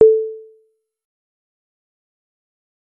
On another side of things I made a test setup to render mathematically generated sounds on the server!
I made a cgi script to run the mathematics package maxima, and some more programs and tools automatically to generate a short audio file with a given mathematical function as the wave rendered in it.
For instance I could take the maxima formula sin(440*x) which would generate a perfect (in 16 bits CD quality) sine wave in the generated sound file which can be downloaded and listened to, but if I feel a bit experimental I could make very complicated formulas, like on this page or just play around with fundamental waves like sine waves multiplied by an exponent: sin(440*%pi*2*x)*exp(-10*x) (or: sin(440*float(%pi)*2*x)*exp(-10*x) to make Fortran not have to crash because it doesn´t know symbolic PI,